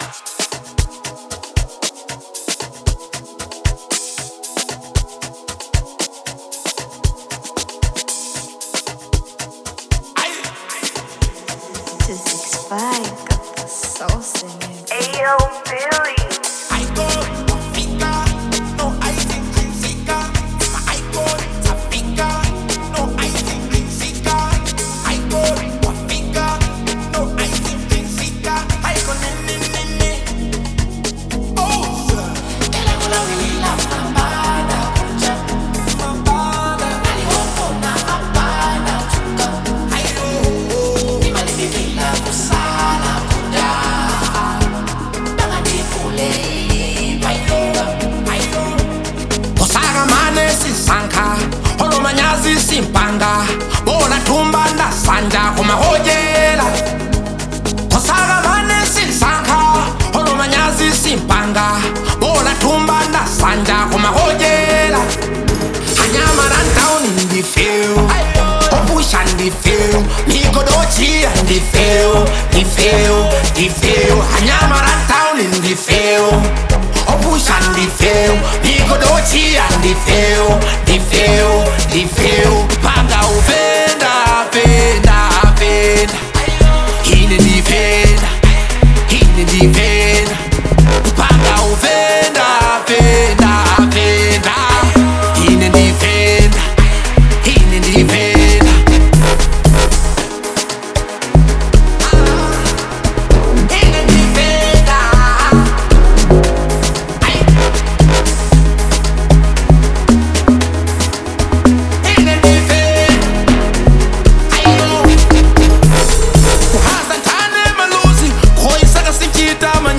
Genre : Amapiano